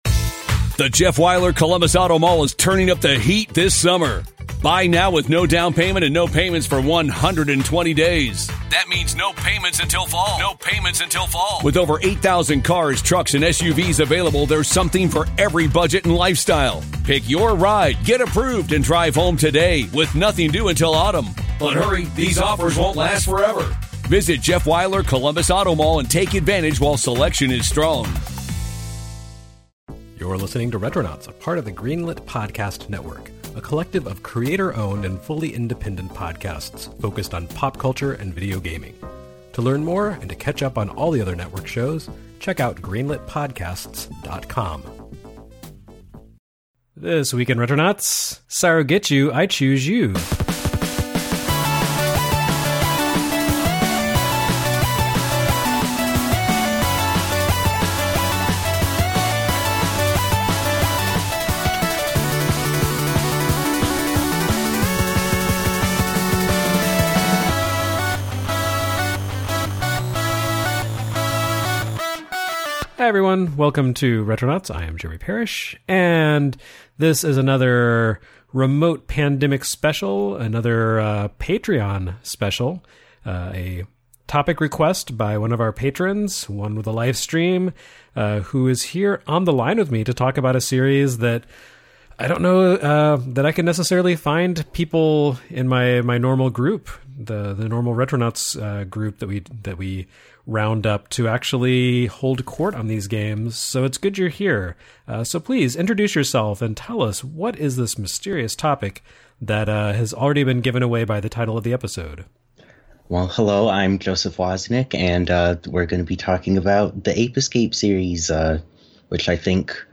A diverse double-header this week
a chat with game archivist